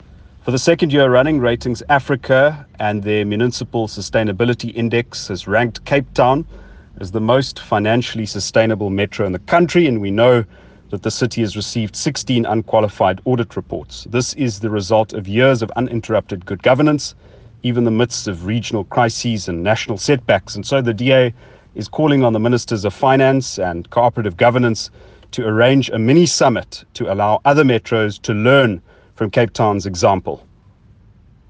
soundbite by Cilliers Brink MP, DA Shadow Minister of Cooperative Governance and Traditional Affairs.